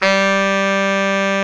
SAX B.SAX 18.wav